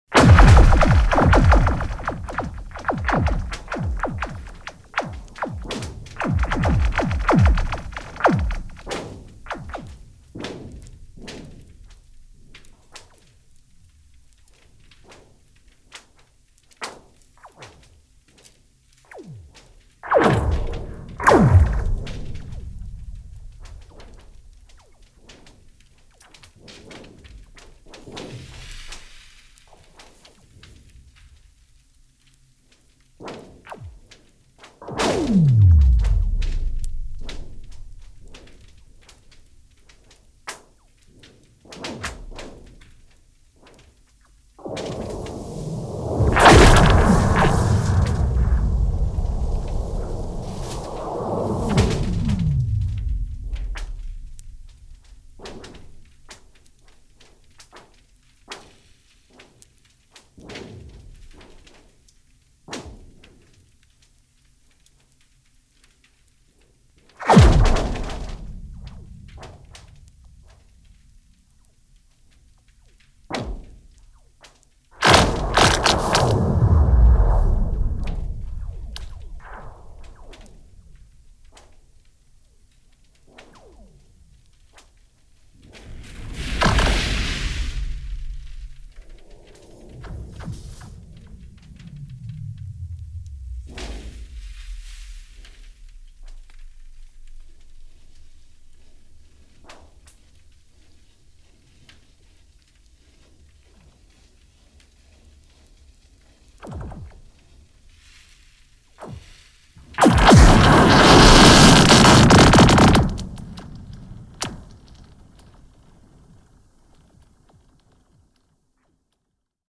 Звуки Байкала
Звуки байкальского льда (звукозапись).
Ozero_Baykal__tresk_lda.mp3